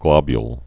(glŏbyl)